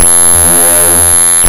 描述：田田！在Garageband中由小号，长号，法国号角，小号部分组成的刺。
标签： 揭示 大张旗鼓 黄铜 赢得
声道立体声